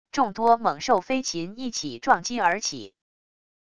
众多猛兽飞禽一起撞击而起wav音频